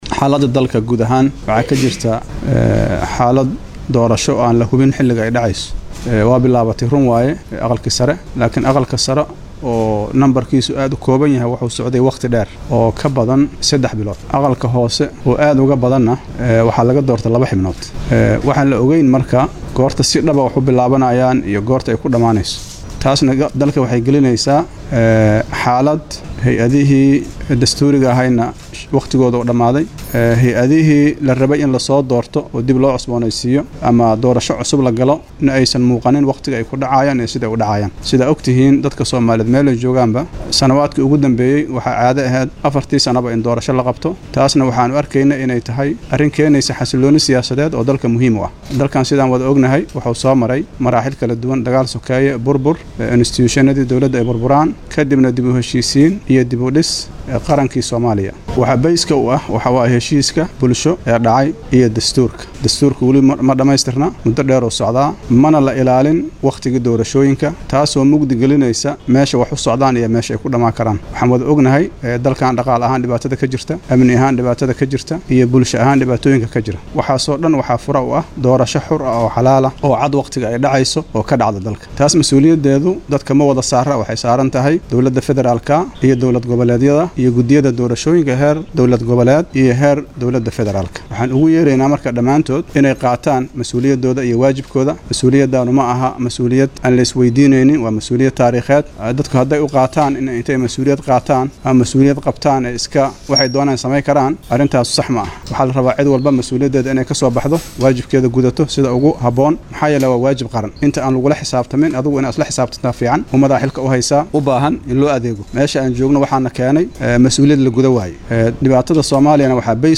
Madaxweynihii Hore ee dowladii KMG Soomaaliya Shariif Shiikh Axmed oo warbaahinta la hadlay ayaa sheegay inay jirto hubanti la,aan ah xiliga doorashada